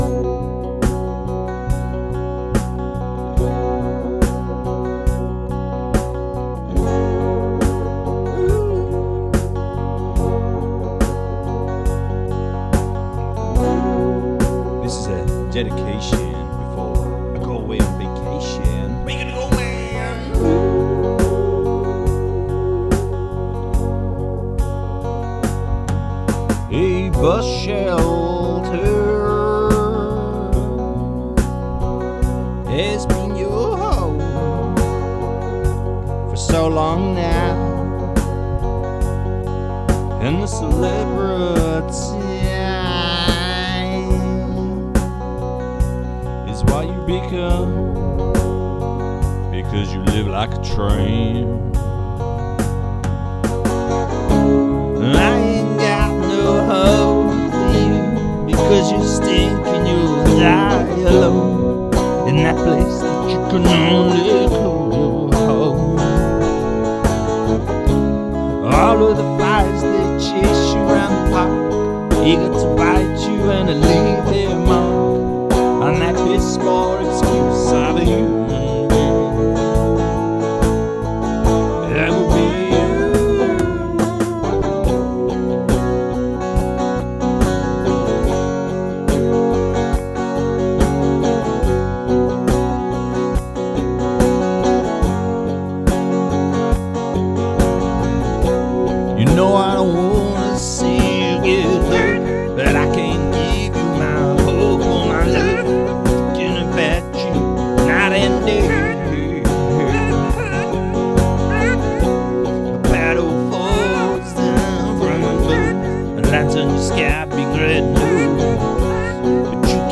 This sure takes you on a rollercoaster of emotion
guitar solo
A majestic chord sequence and tormented vocal delivery